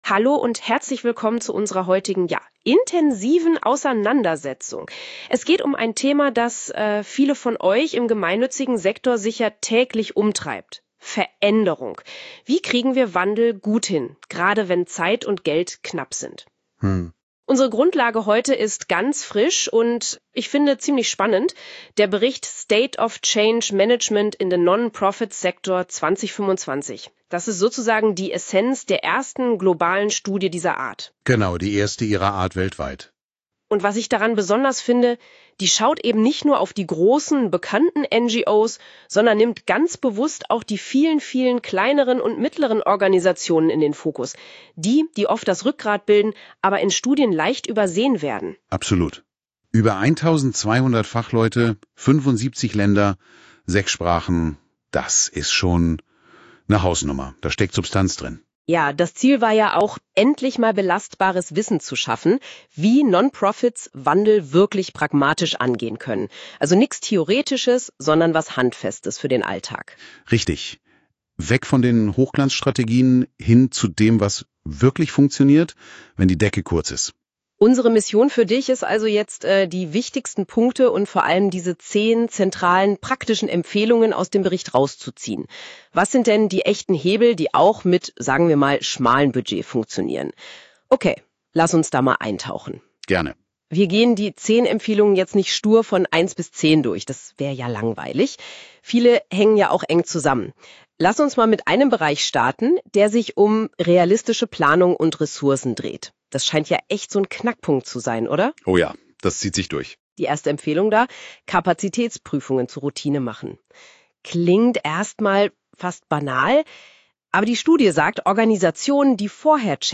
Audio summary of the recommendations
These audio summaries were generated automatically using Google NotebookLM. Each provides an AI-assisted overview of the Top 10 Recommendations document.